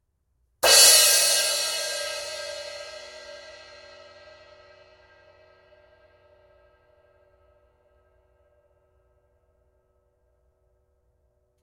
Zildjian FX 17" El Sonido multi-crash ride
Cette cymbale ultra polyvalente conviendra aussi bien à des rythmiques Latin, Jazz que Rock.
Catégorie : crash/ride
Son : brillant